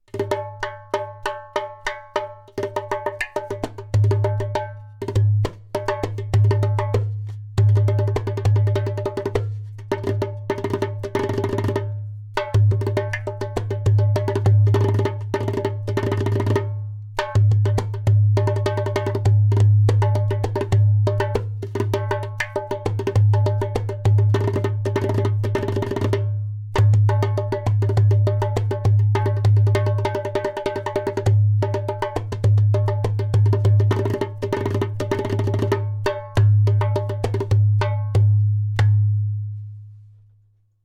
100 bpm:
Sharp, deep sound with the raw beauty of the desert.
• Taks with harmonious overtones.
• Deep bass for a solo darbuka.
• Loud clay kik/click sound for a solo darbuka!